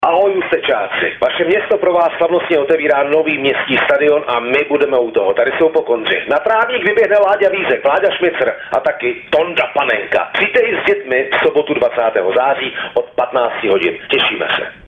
Jedno hlášení bylo tiché a nesrozumitelné, a druhé prozměnu zase moc hlasité.
Narážel tím na hlášení o konání půlmaratonu, které cestující v ústecké MHD provázelo od začátku září, a poté také na slavnostní otevření Městského stadionu, které v trolejbusech a autobusech zvalo cestující v týdnu od 17. do 22. září.
- Hlášení o slavnostním otevření Městského stadionu si